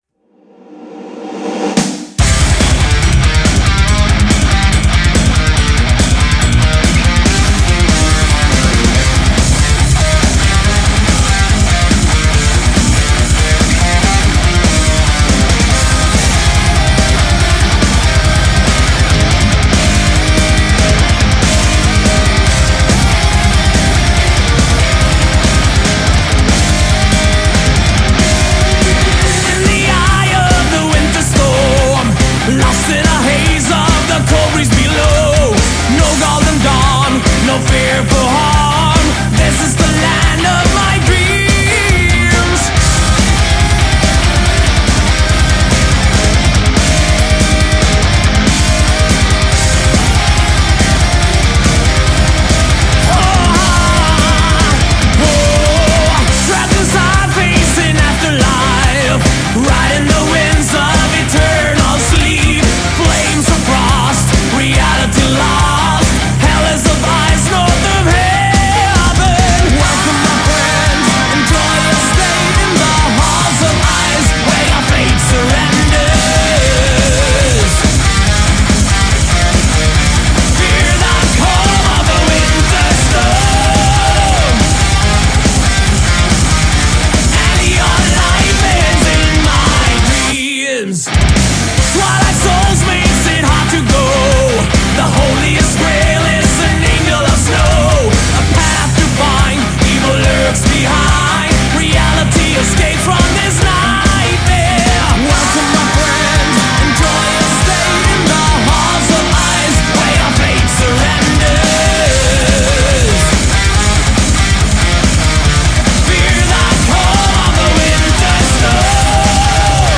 Жанр: Power Metal